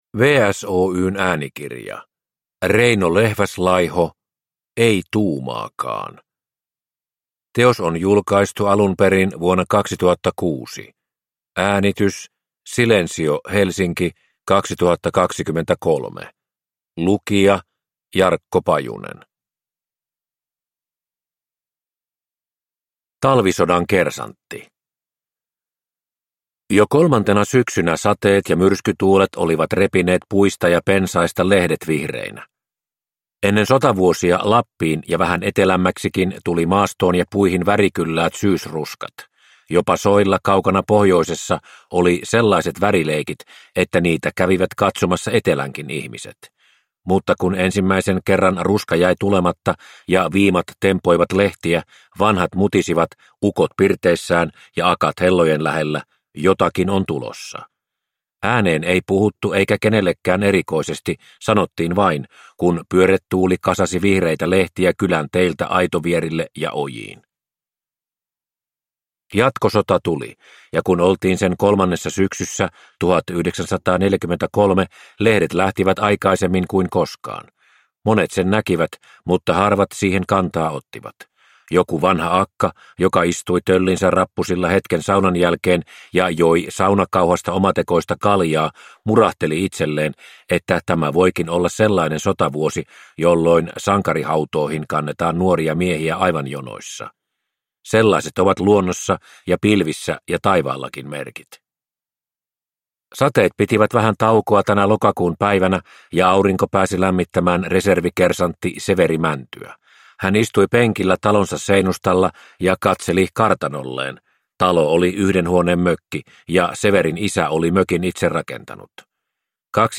Ei tuumaakaan! – Ljudbok